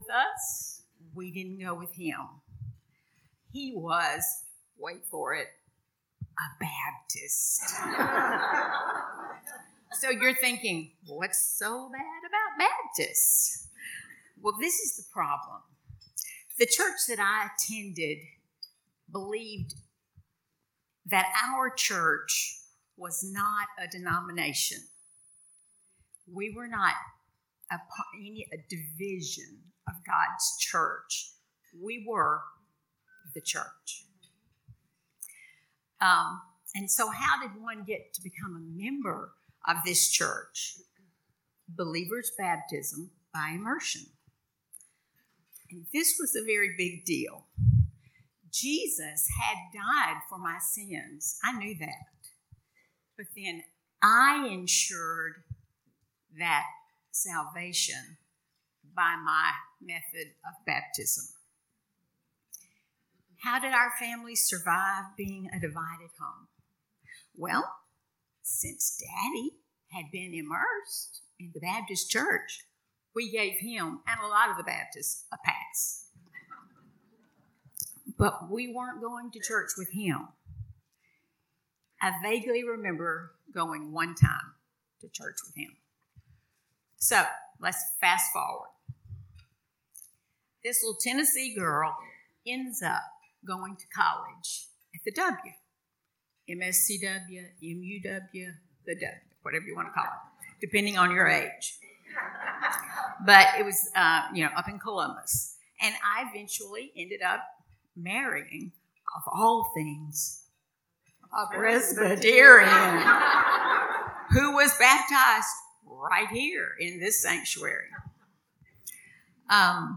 Renewing Grace: Women’s Ministry Spring Luncheon 2023
FPC-Spring-Womens-Luncheon-2023.mp3